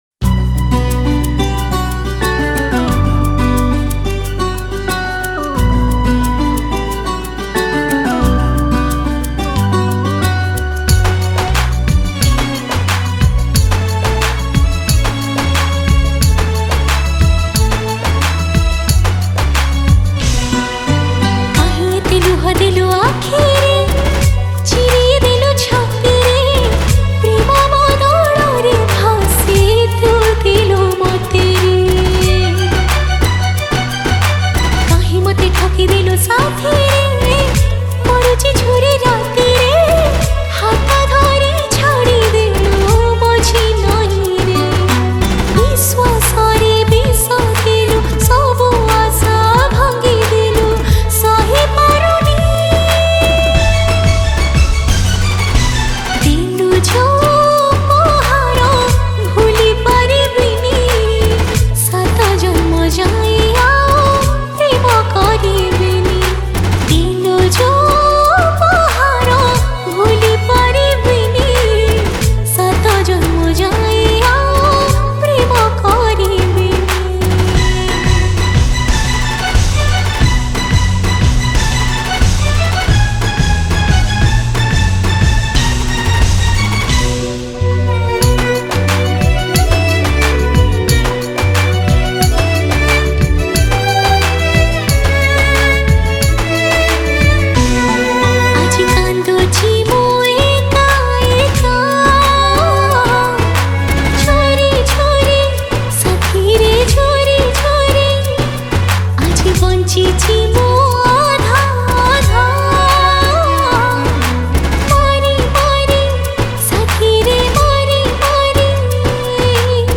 Song Type :Sad